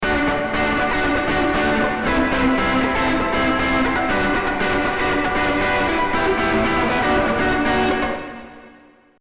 В данном случае гитара обработана двумя эффектами на одних и тех же настройках: Wampler Pinnacle Distortion и Strymon Flint (80’s hall Reverb). Только в первом случае использована классическая связка с дисторшном ДО ревера, а во-втором - с дисторшном ПОСЛЕ ревера.
Rever-After-Distortion.mp3